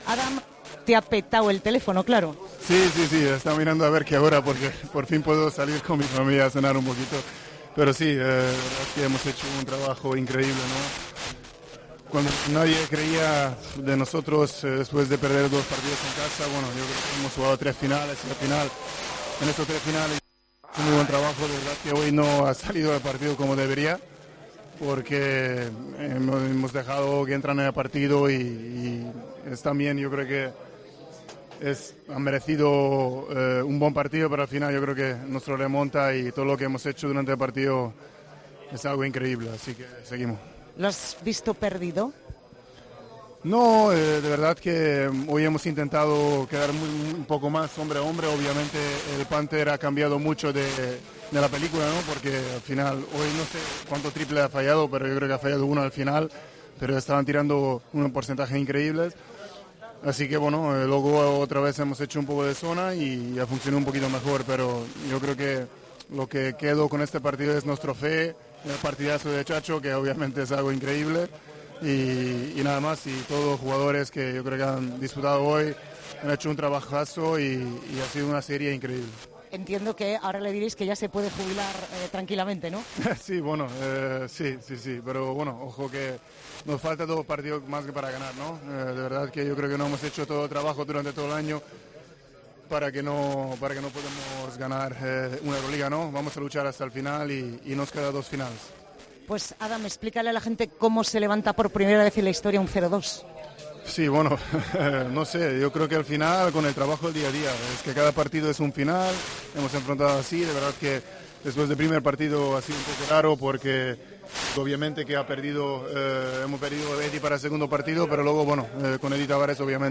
habla con el jugador del Real Madrid tras la remontada histórica que clasificó a los blancos para la Final Four.